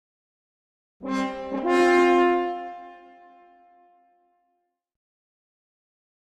Horn Fanfare Victory Signal